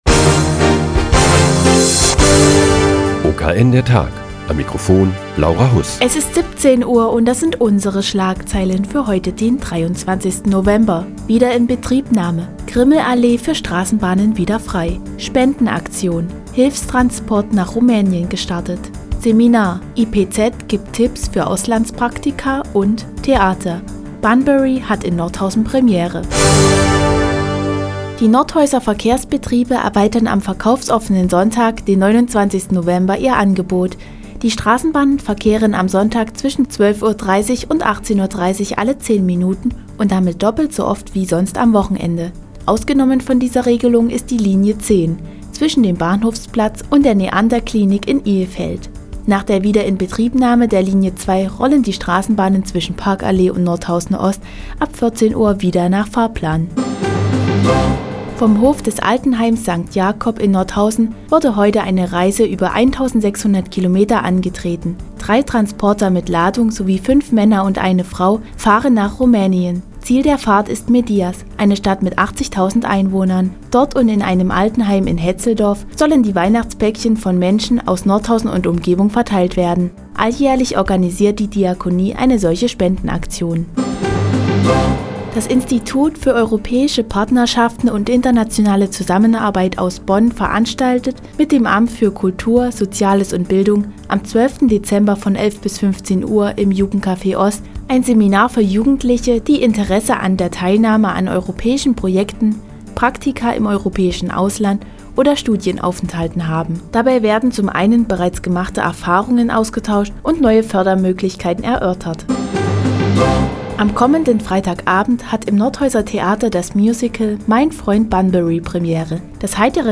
Die tägliche Nachrichtensendung des OKN ist nun auch in der nnz zu hören. Heute geht es um einen Hilfstransport nach Rumänien und die Theaterpremiere am kommenden Freitag.